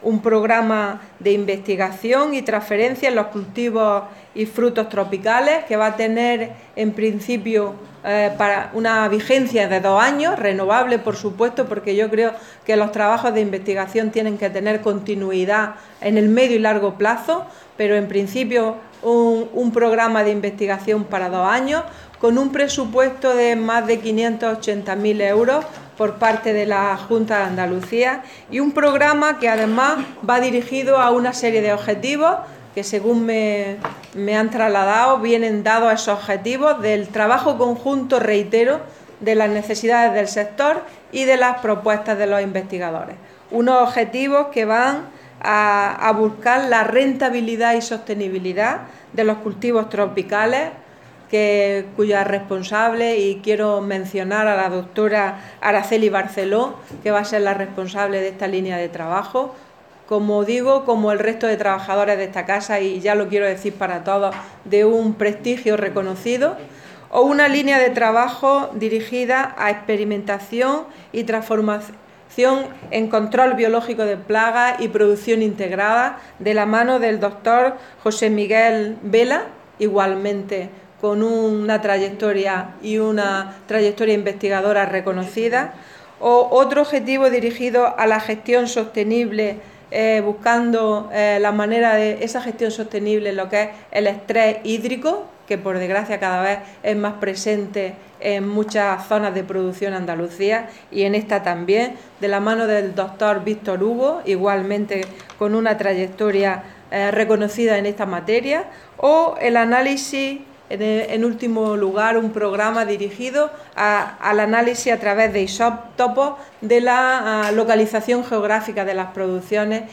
Declaraciones Carmen Ortiz sobre el sector